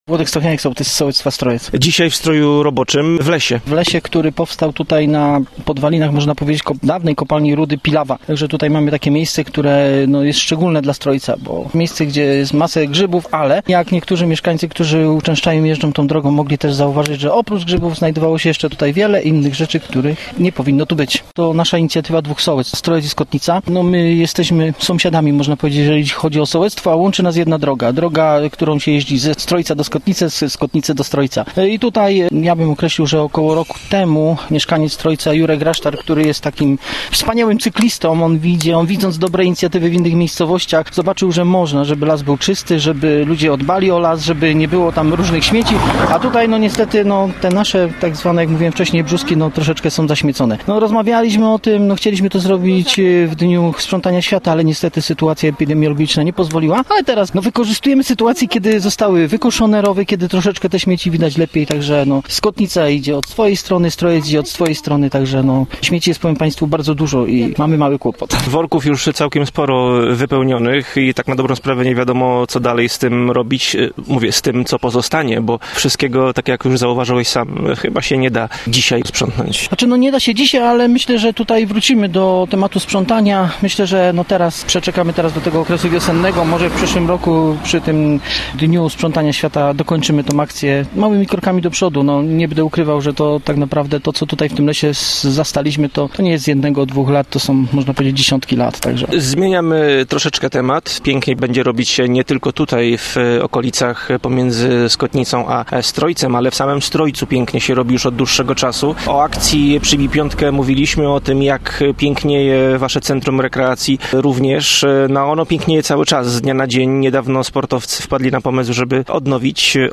Gościem Radia ZW był Włodzimierz Stochniałek, sołtys Strojca (gm. Praszka)